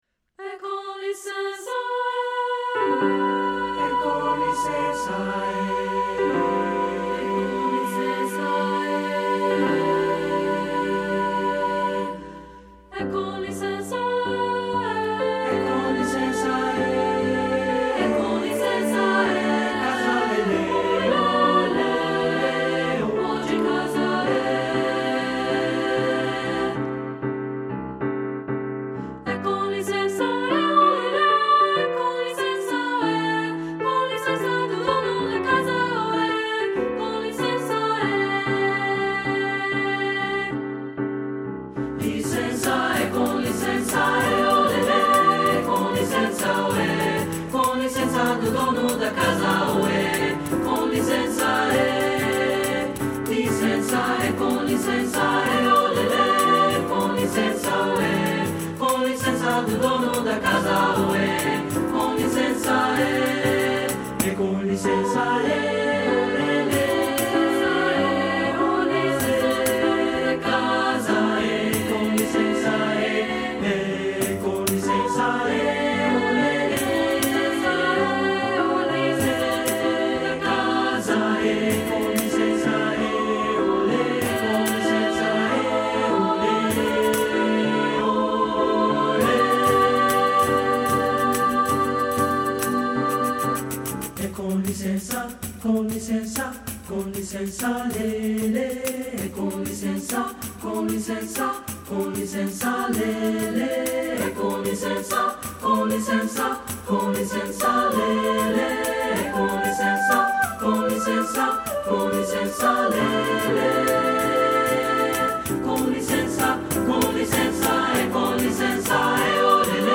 • Piano
• Tambourine
Studio Recording
Ensemble: Three-part Mixed Chorus
Key: G major
Tempo: dance-like (q. = 96)
Accompanied: Accompanied Chorus